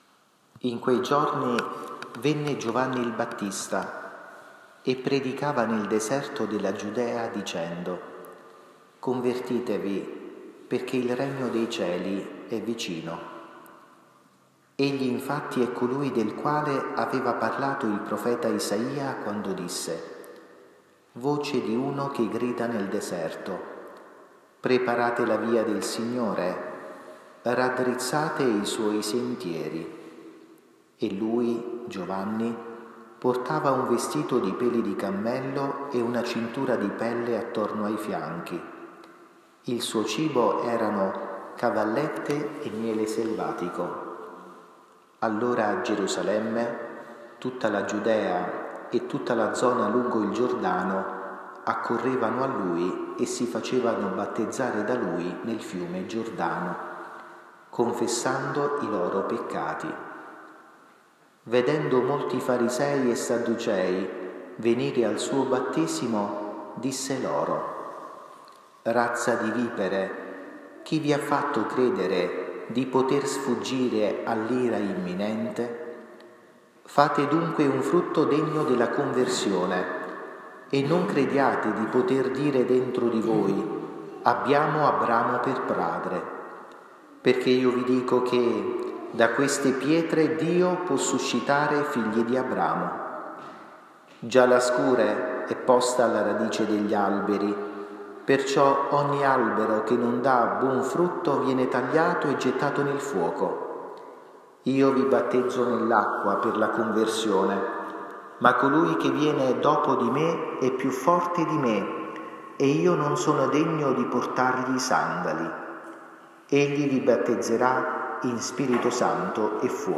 II DOMENICA DI AVVENTO – 4 dicembre 2022 (Anno A)
omelia-domenica-4-dicembre-22.mp3